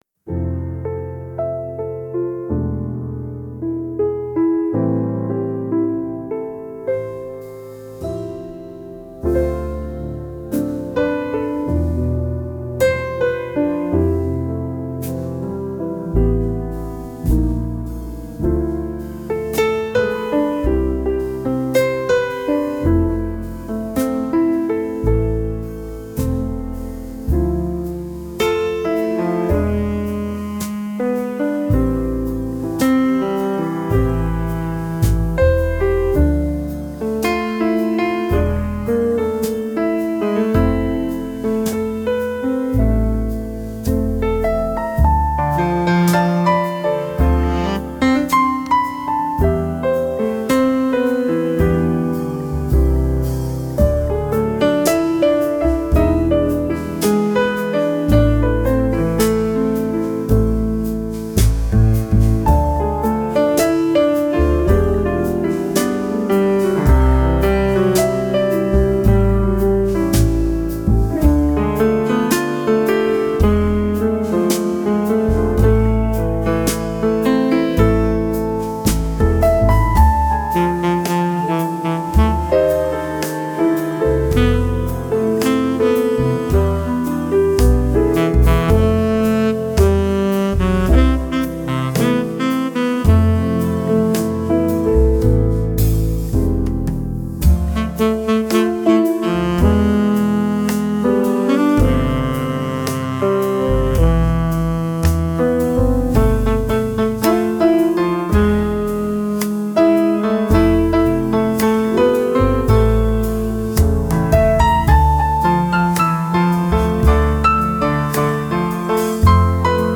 Джем сейшн (импровизация)